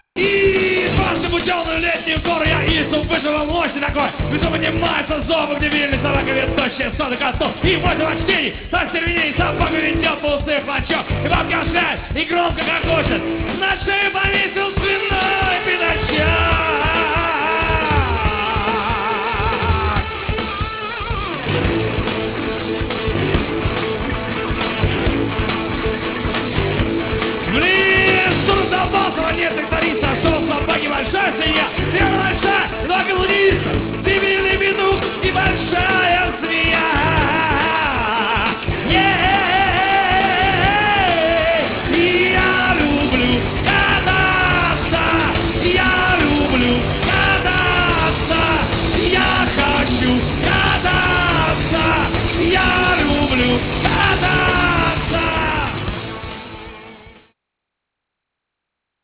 2-е Международное байк-шоу (30.08.96)